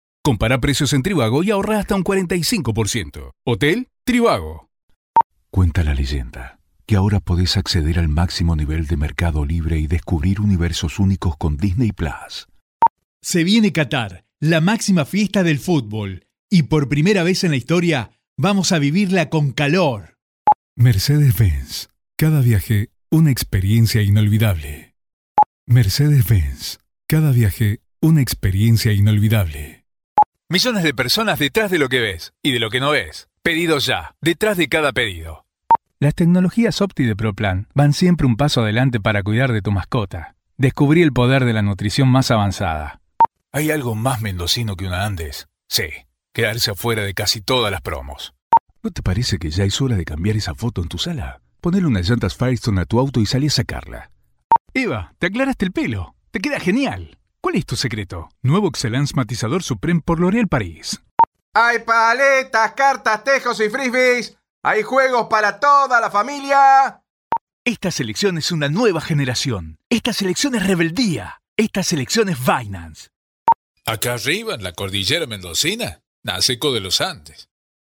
Adult male voice, warm and trustworthy, with clear diction and strong on-mic presence.
Studio Quality Sample
Commercials